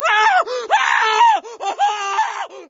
scream2.ogg